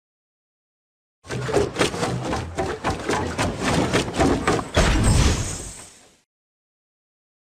Sound_FinishBuilding.mp3